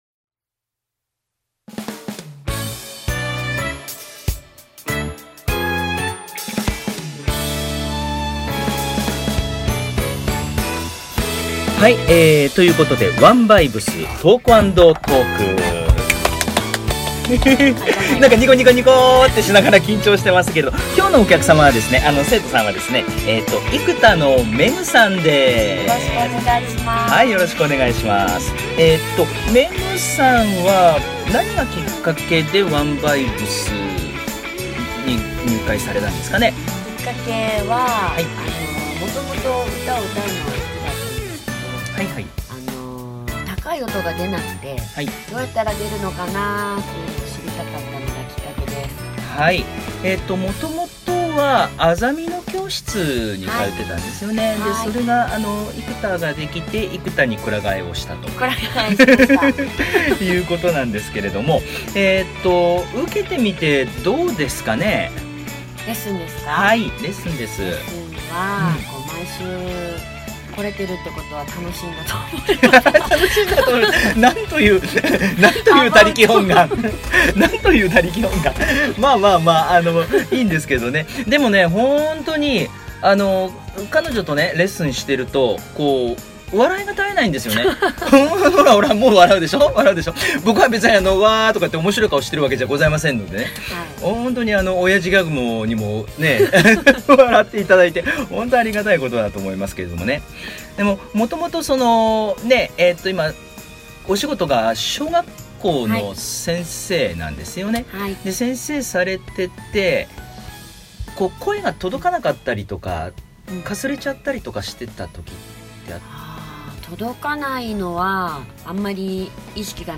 ここで毎週、レッスンをしていますし、今回のトーク＆トークもここで収録しました。(^^♪